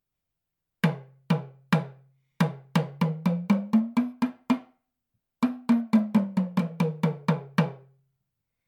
トーキングドラム マリ カンガバ工房製 タマ
Sサイズ 胴直径11.5 cm x 長さ 24 cm
トーキングドラム・タマ音 TAMA soundこのタマの音を聴く
このサイズでも本格仕様、軽やかに小気味よく響きます。